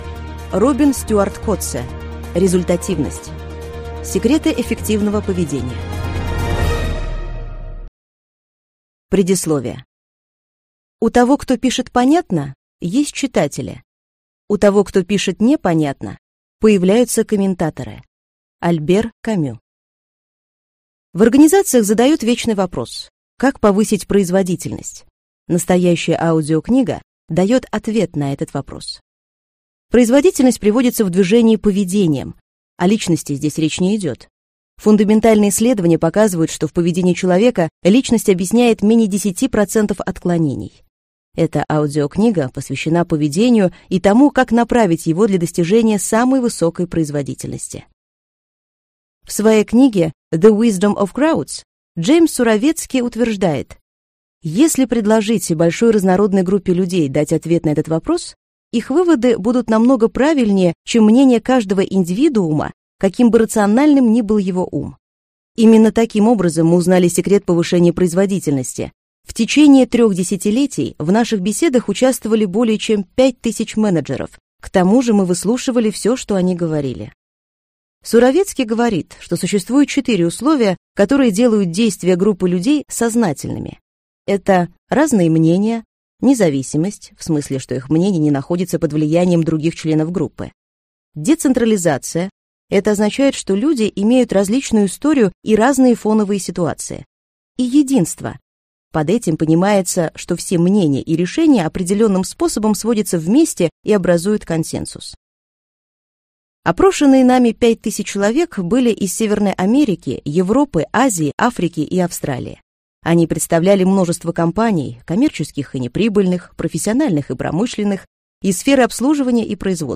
Аудиокнига Результативность: Секреты эффективного поведения | Библиотека аудиокниг